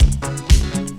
DISCO LOO02L.wav